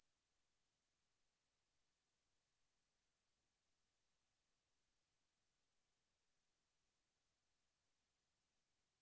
Noise when recording stereo-mix
When I record system audio with stereo-mix, there is a noise throughout the recording.
I’ve added a sample of only the noise, you might have to increase volume to the max to hear it.
I’m recording with the Windows WDM-KS setting and Audacity 2.0.4 for Windows 7 (32-bit on 64-bit machine).
Frying mosquitoes.